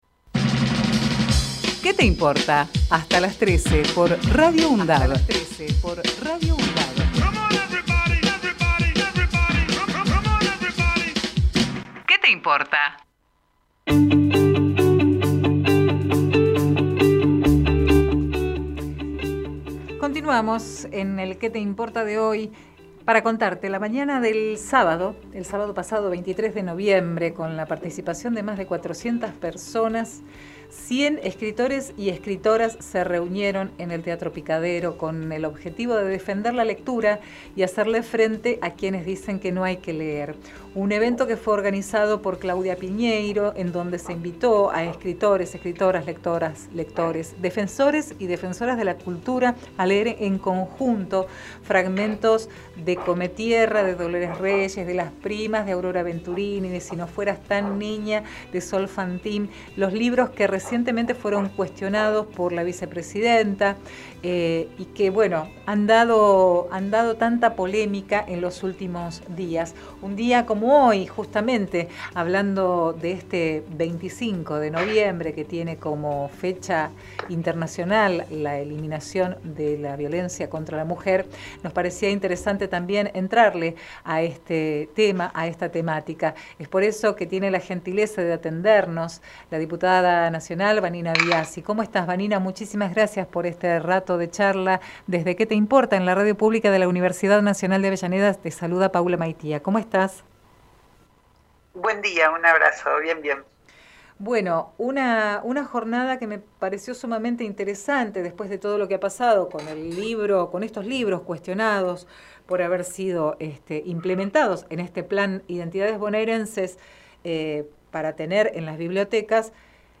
QUÉ TE IMPORTA - VANINA BIASSI Texto de la nota: Compartimos con ustedes la entrevista realizada en "Qué te importa?!" a Vanina Biassi, Diputada del Partido Obrero Archivo de audio: QUÉ TE IMPORTA - VANINA BIASSI Programa: Qué te importa?!